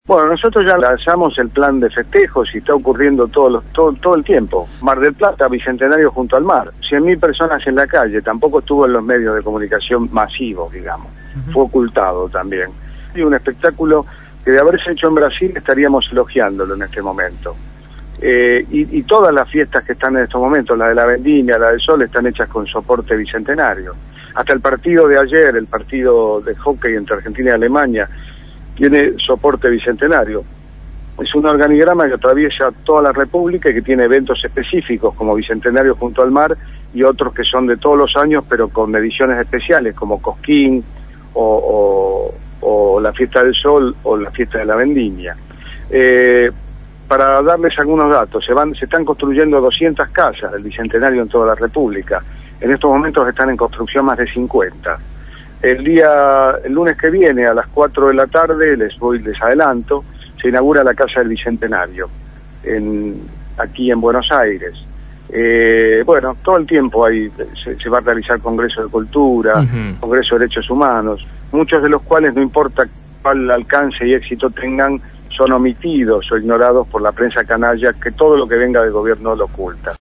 Jorge Coscia, Secretario de Cultura de la Nación, fue entrevistado